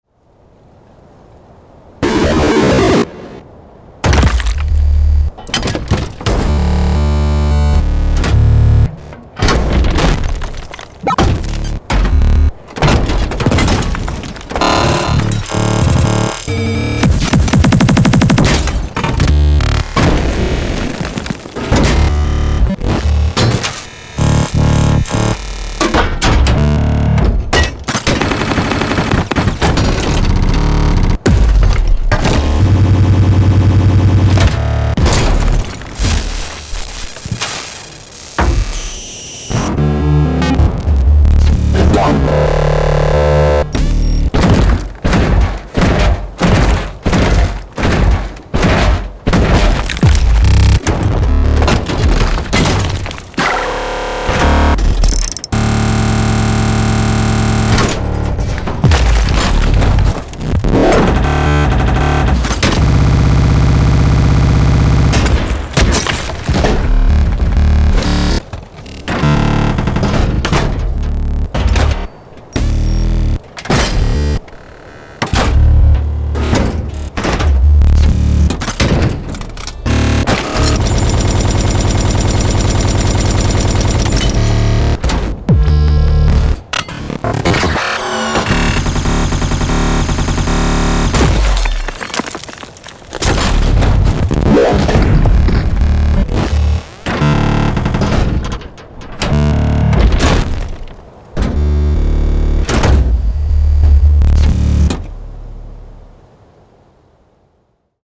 Bluezone_Annihilation_Mech_Sound_Effects_Demo.mp3